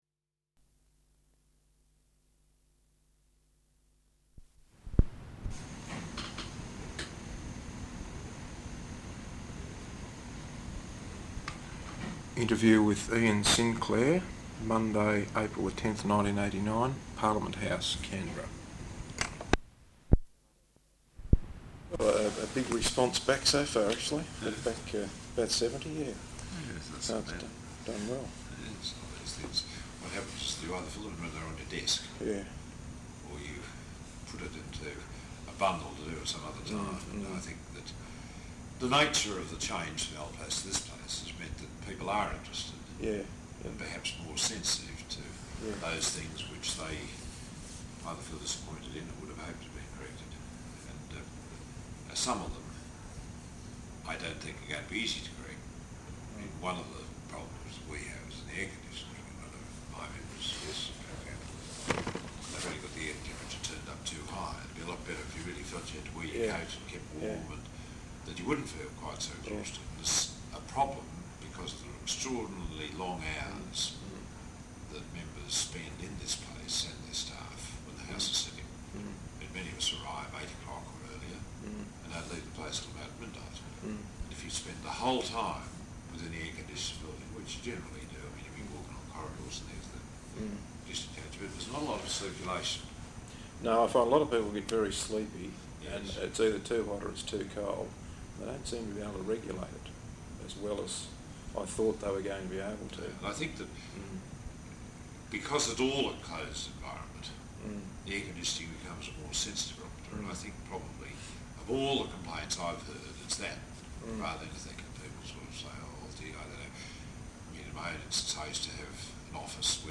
Interview with Ian Sinclair, Monday April 10th 1989, Parliament House, Canberra.